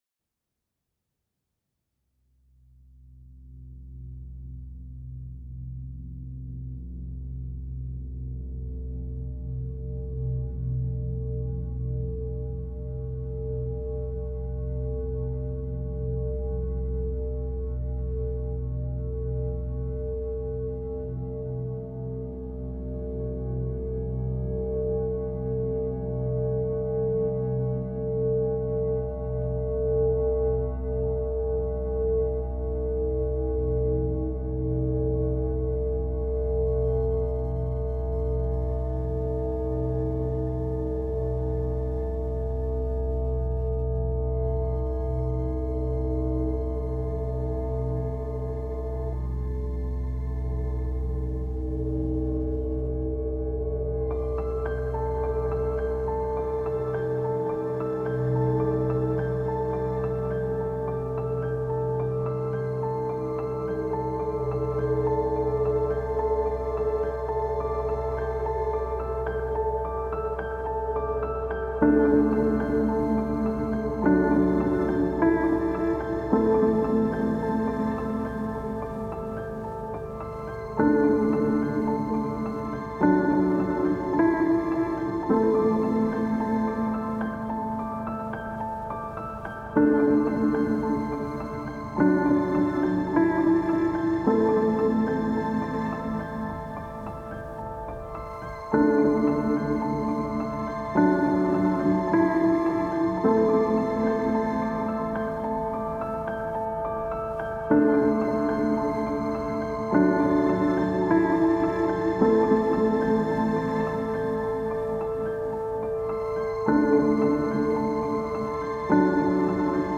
Rain Thursday
Regn Torsdag (1).mp3
CA_IDNO en -00149 Title en Rain Thursday Description en A sound made for a person that are looking for their groove.
Sound/music Location en Gävle Interaction Time en Up to 10 minutes Collective en Sweden Type of Contribution en Individual Media Regn Torsdag (1).mp3